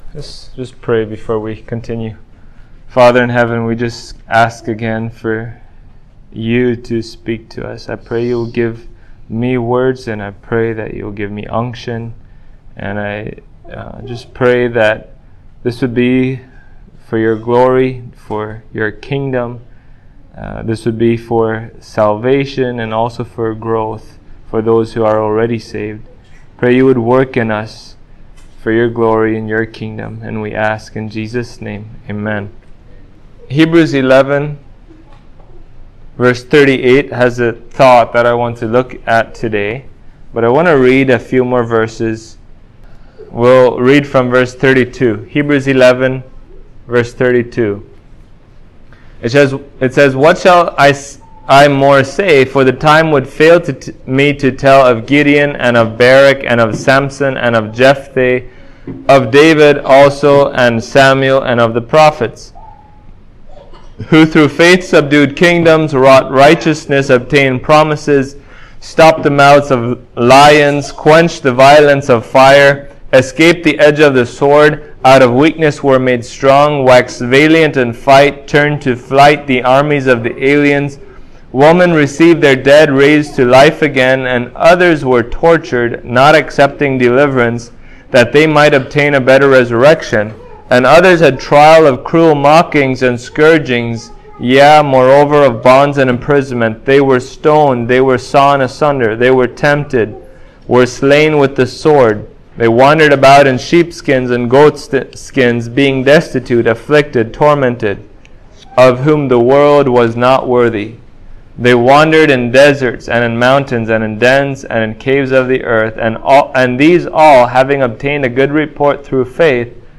Hebrews 11:38 Service Type: Sunday Morning Persecution should make the true Believers joyful.